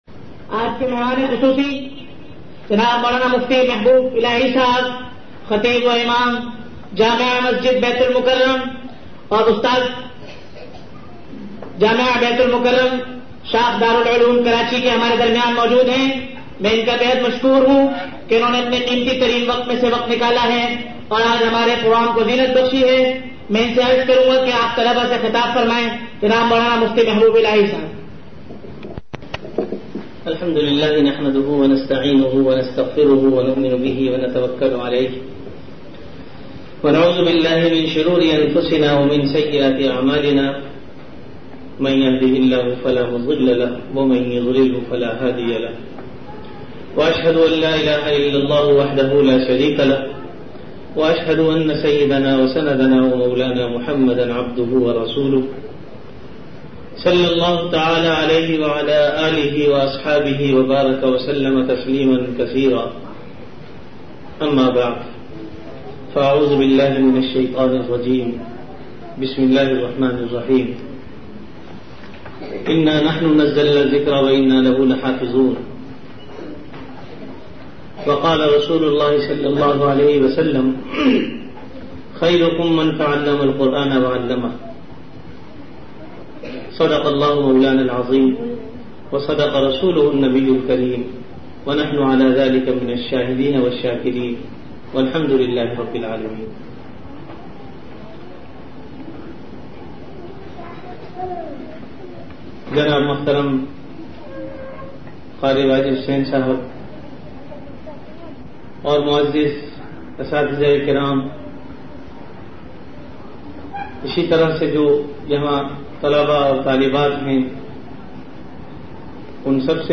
Delivered at Jamia Masjid Bait-ul-Mukkaram, Karachi.
Bayan at Madarsa Darul Uloom Ayesha Baee Jamal
Bayan at Madarsa Darul Uloom Ayesha Baee Jamal.mp3